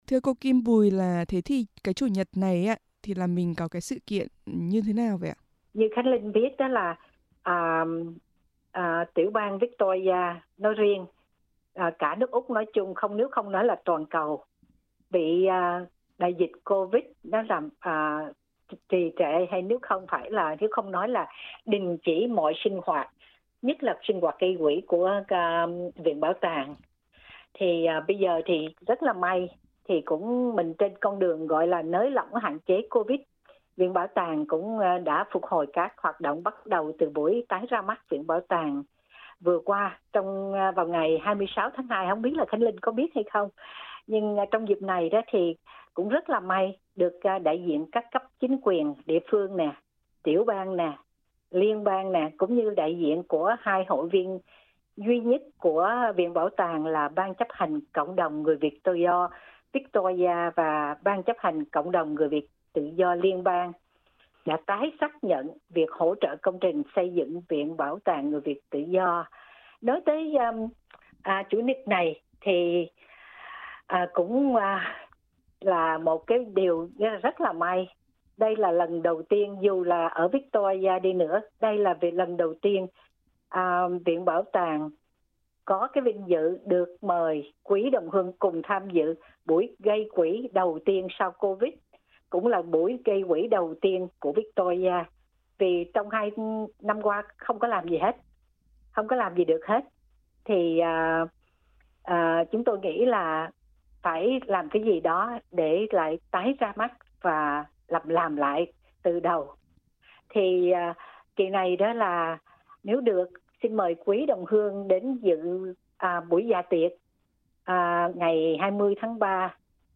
Trò chuyện với SBS Vietnamese